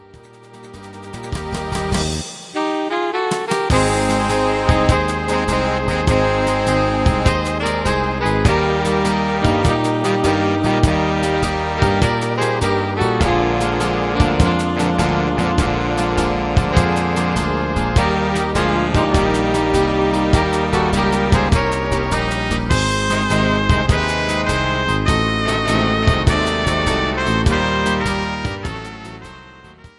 instrumental Saxophon